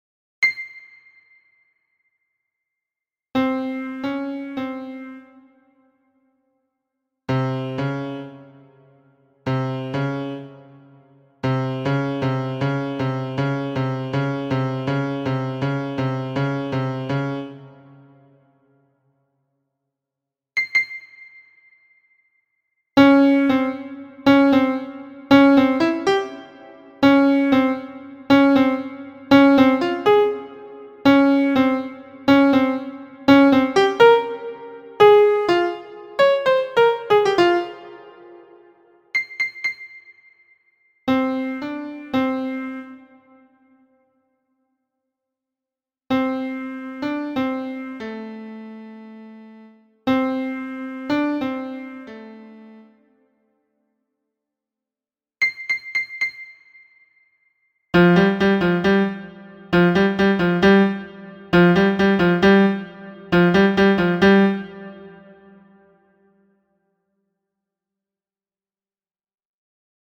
This section contains some MIDI files that demonstrate some interval in the context of some songs.